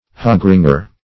Hogringer \Hog"ring`er\, n. One who puts rings into the snouts of hogs.